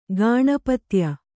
♪ gāṇapatya